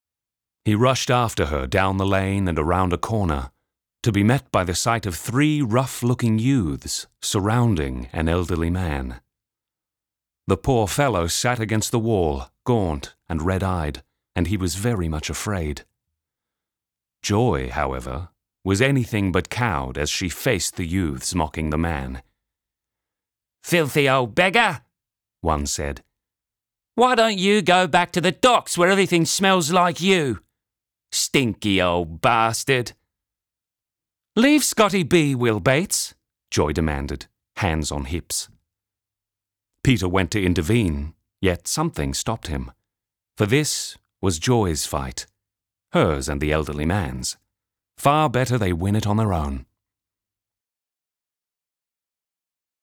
Audiobook_UK Accent_Character